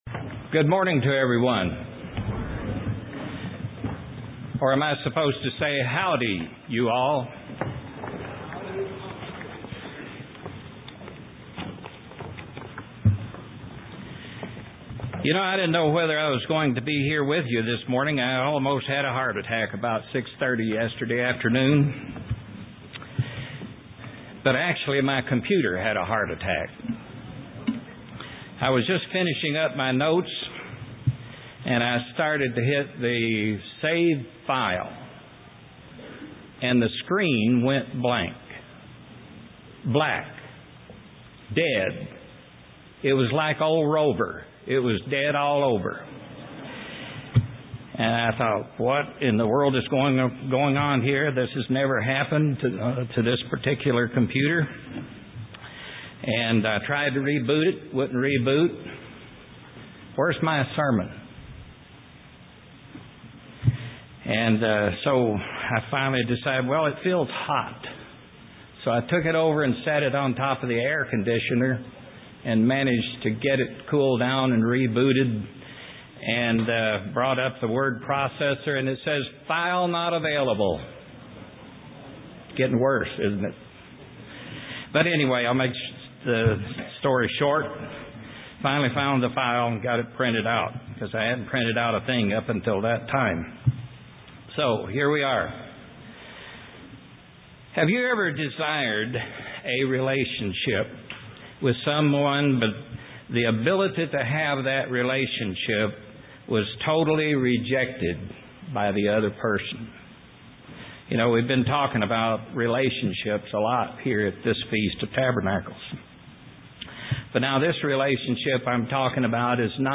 Day five FOT New Braunfels.
UCG Sermon Studying the bible?